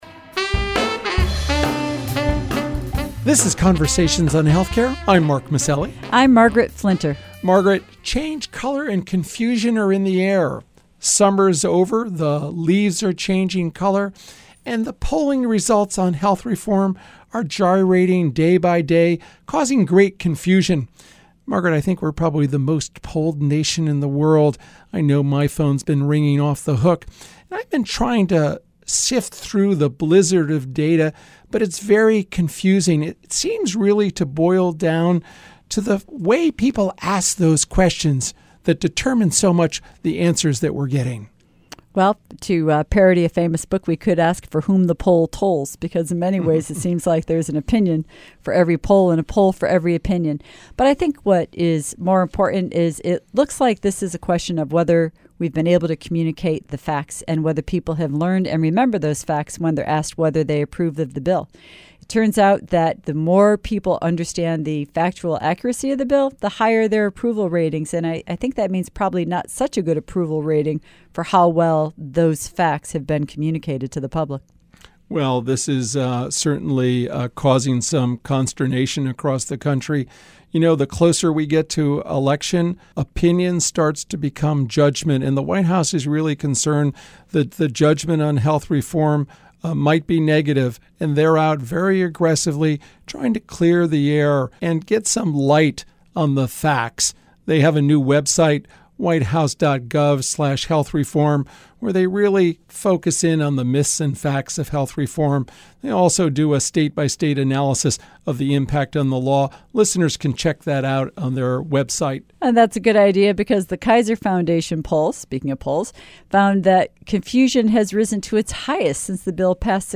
speak with Tina Tchen, executive director of the White House Council on Women and Girls and White House director of the Office of Public Engagement, about health reform and the broader issues of economic and social equality for women and girls.